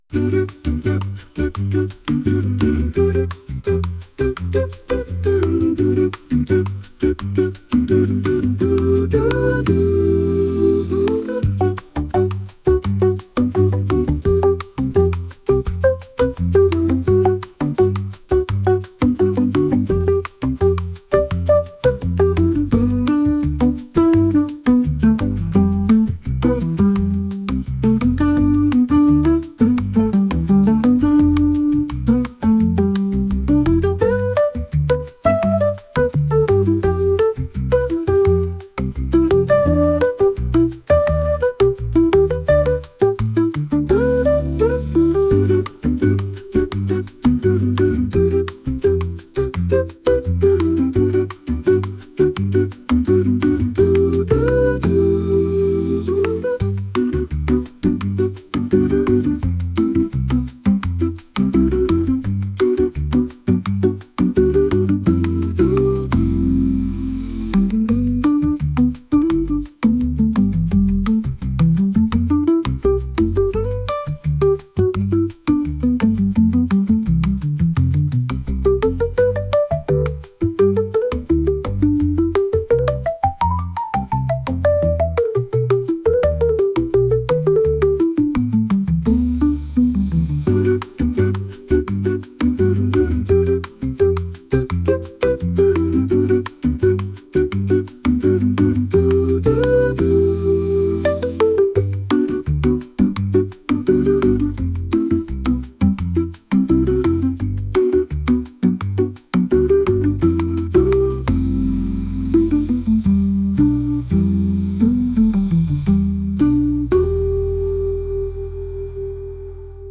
アカペラを含んだボサノバ曲です。